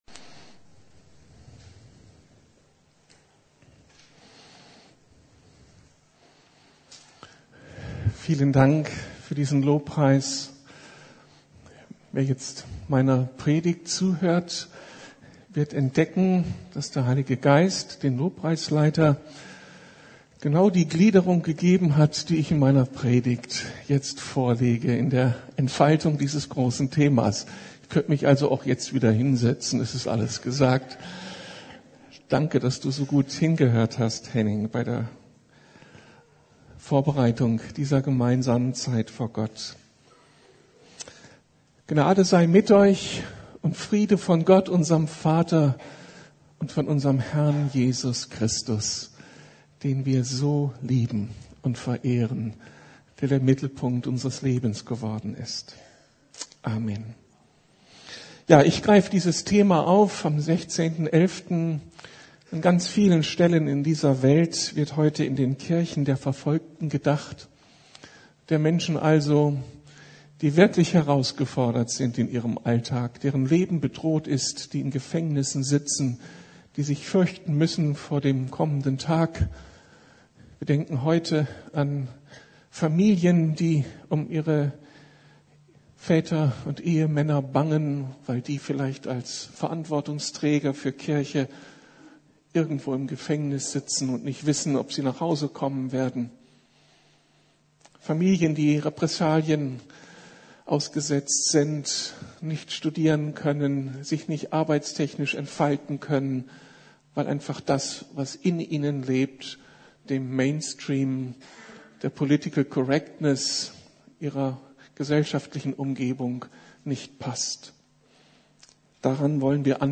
Die andere Seite des Lebens mit Jesus Christus ~ Predigten der LUKAS GEMEINDE Podcast